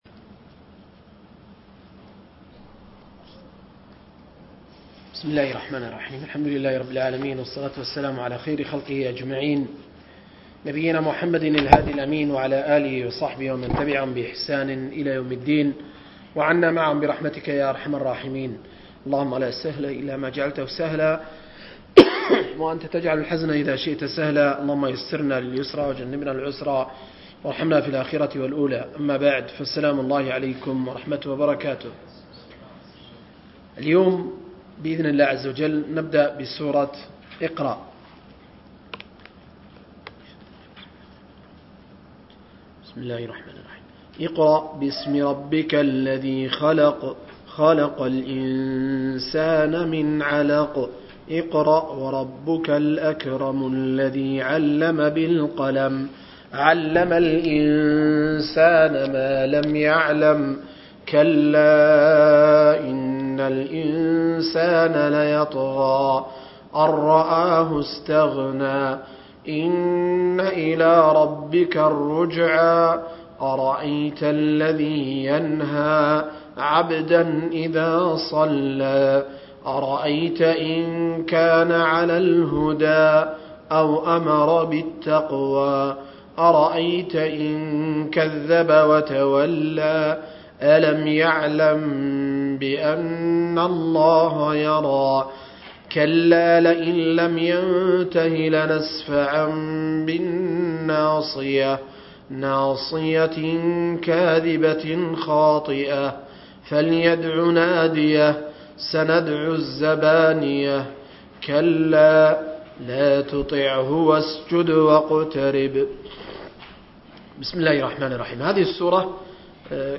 درس